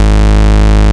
VES2 Bass Shots
VES2 Bass Shot 061 - G#.wav